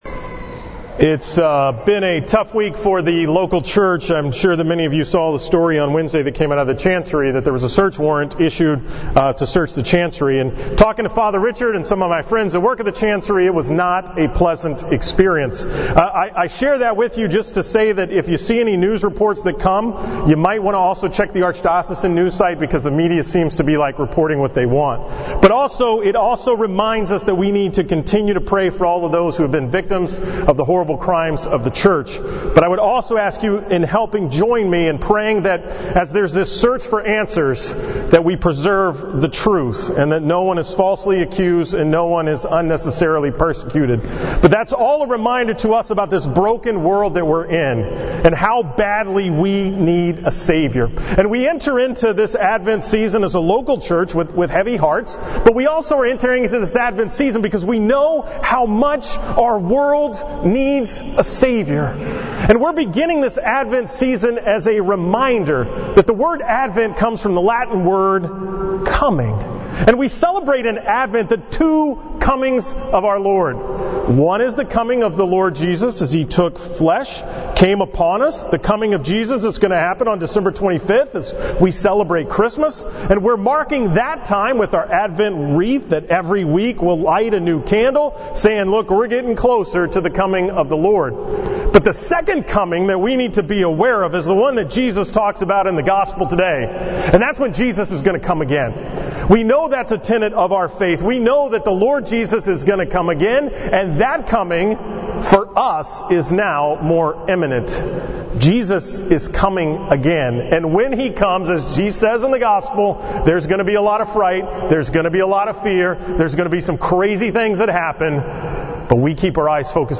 From the 5:30 pm Mass on December 2, 2018.